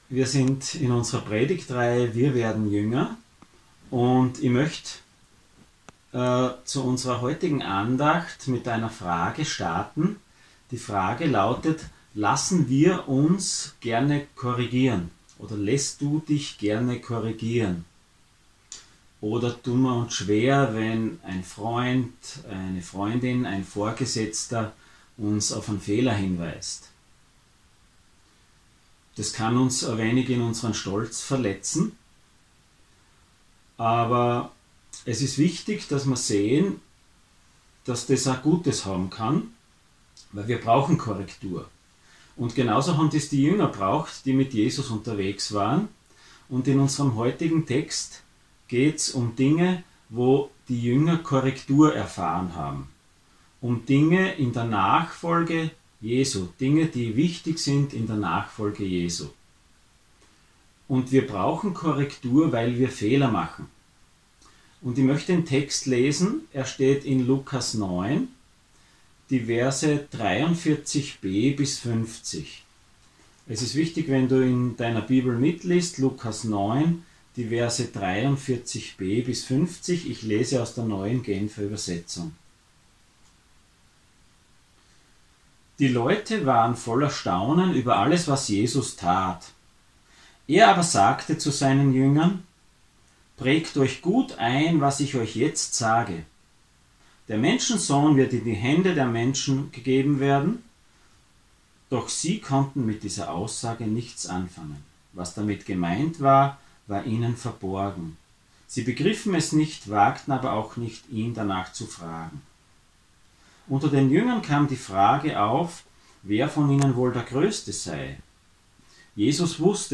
Dienstart: Sonntag Morgen